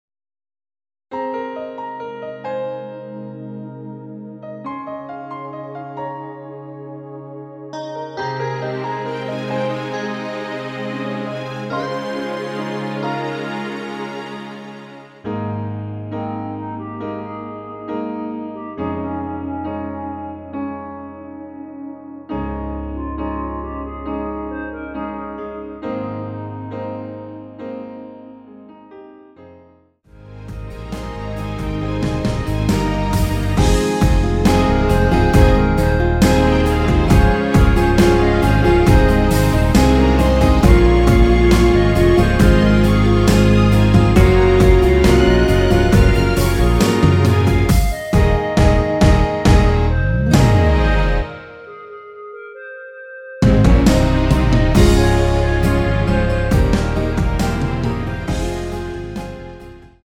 원키에서(-10)내린 멜로디 포함된 MR입니다.(미리듣기 참조)
Ab
앞부분30초, 뒷부분30초씩 편집해서 올려 드리고 있습니다.